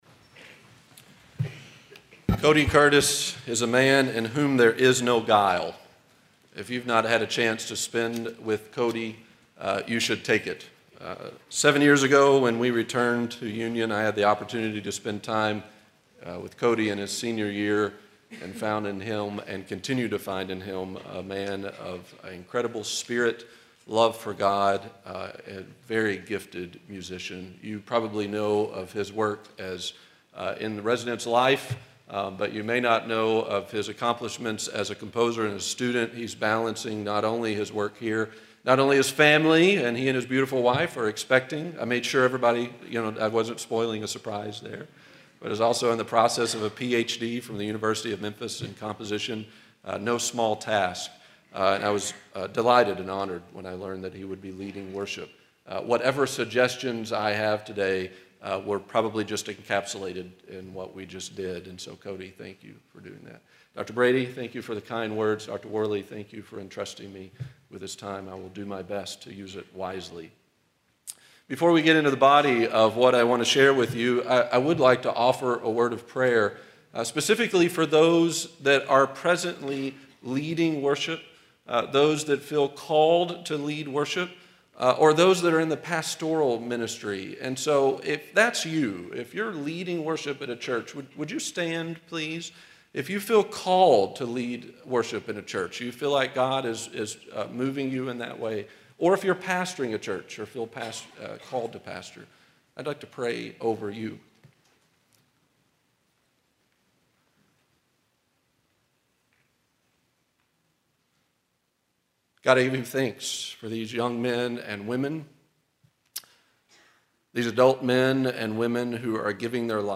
Union University, a Christian College in Tennessee
Chapels